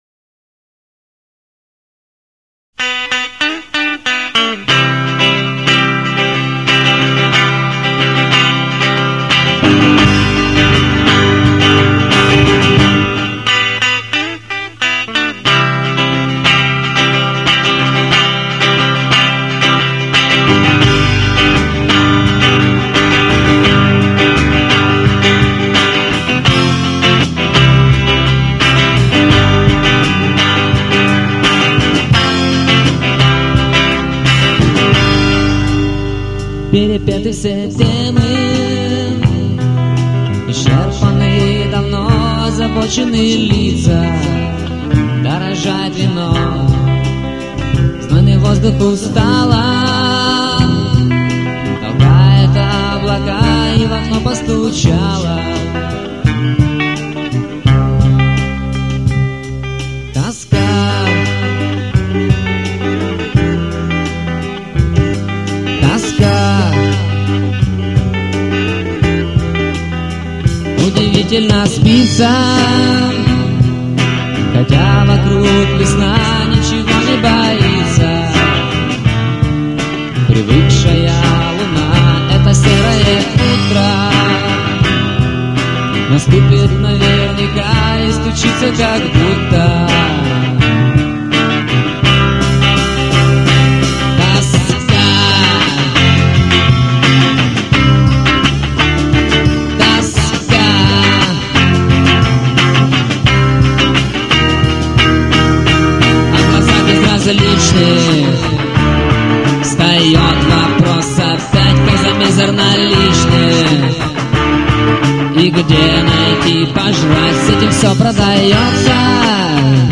Тоска (ПІСНЯ)